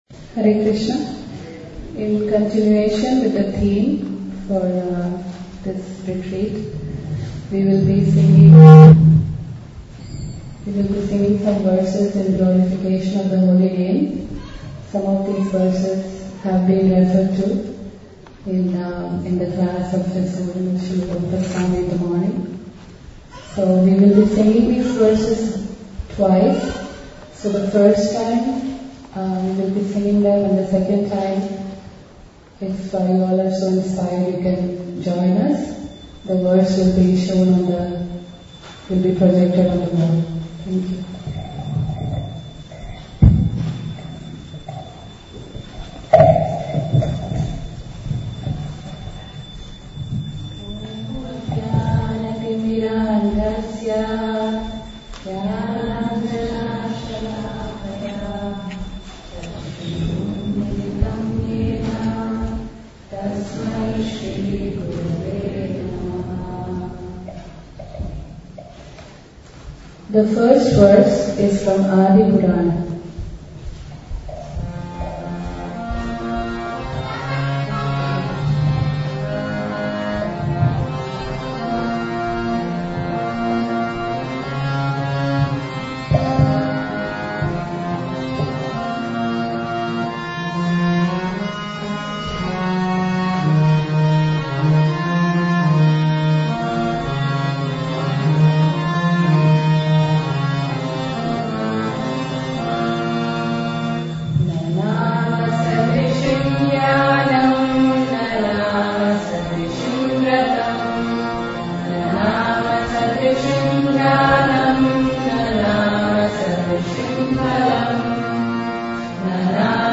REC11–Recitation of Verses glorifying the Holy Name 1